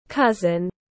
Anh chị em họ tiếng anh gọi là cousin, phiên âm tiếng anh đọc là /ˈkʌz.ən/.
Cousin /ˈkʌz.ən/